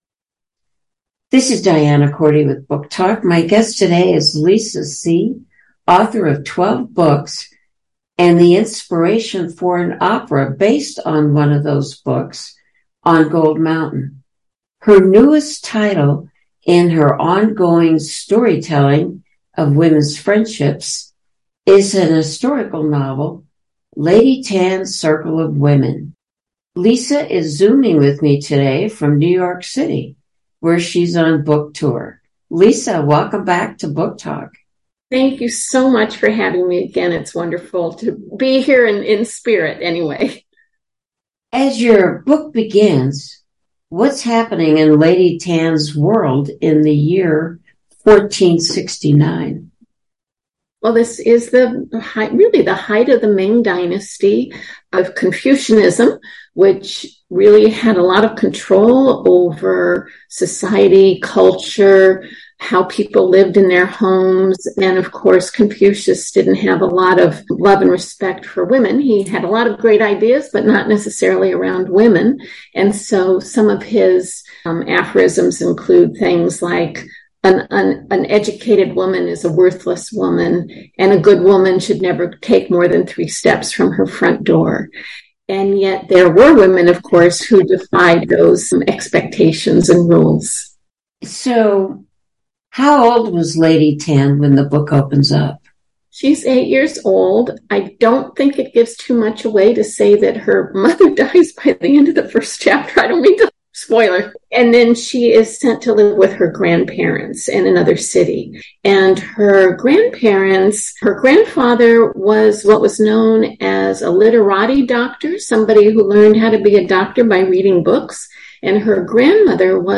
Top authors are interviewed on this program that captures their words about their books and ideas and often the story behind the story.